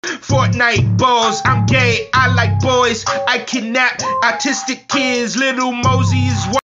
hmm1.mp3